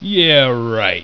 In 1995, Wired magazine's AOL forum located Elwood Edwards -- whose voice recorded the sound file "You've Got Mail" -- and had him record ten additional sound files.